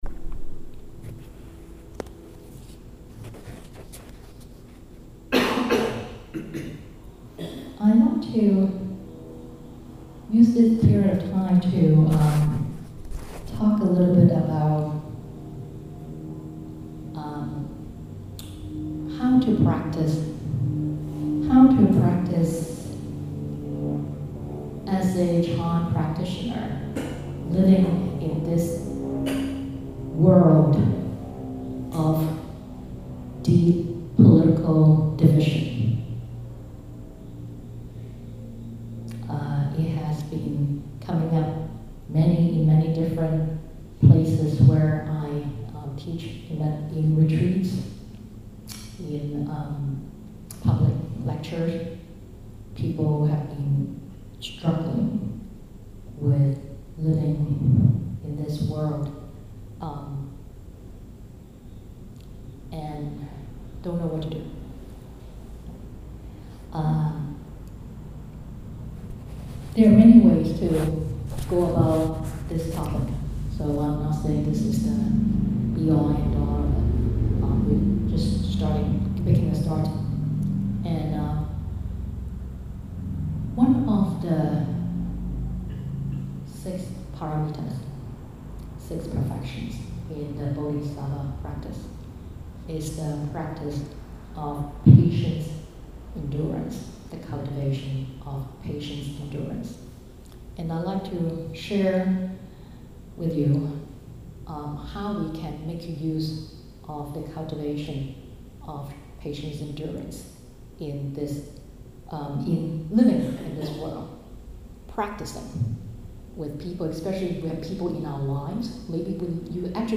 This talk was given on September 15, 2019 at the New Jersey chapter of DDMBA in Edison, NJ. https